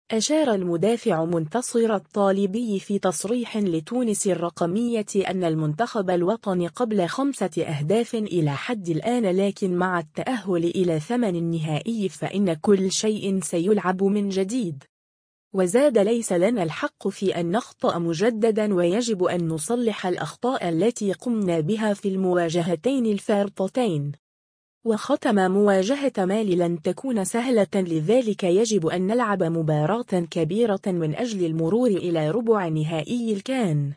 أشار المدافع منتصر الطالبي في تصريح لتونس الرقمية أنّ المنتخب الوطني قبل 5 أهداف إلى حد الآن لكن مع التأهّل إلى ثمن النهائي فإنّ كل شيء سيُلعب من جديد.